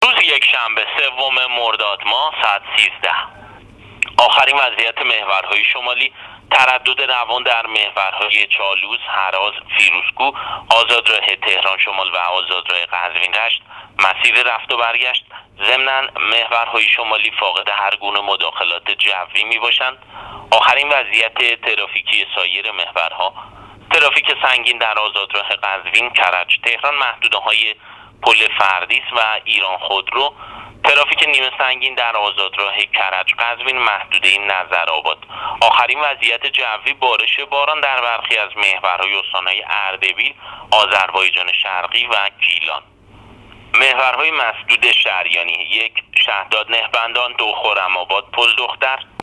گزارش رادیو اینترنتی از آخرین وضعیت ترافیکی جاده‌ها تا ساعت ۱۳ سوم مرداد